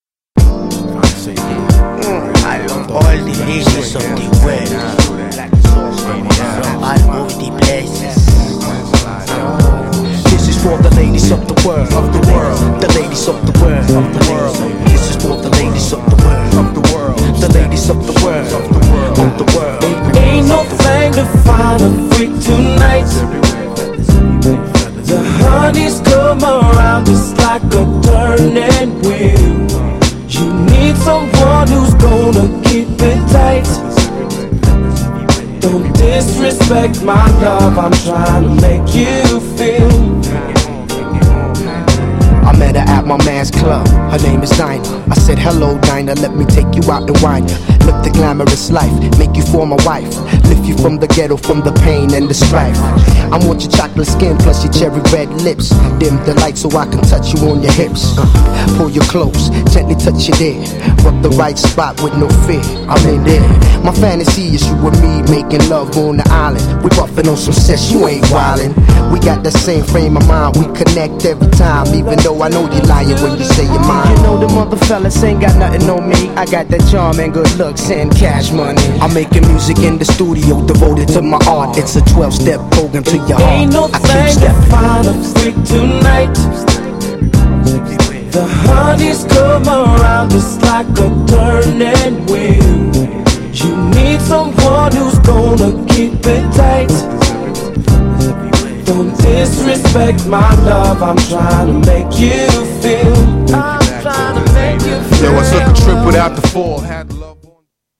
GENRE R&B
BPM 91〜95BPM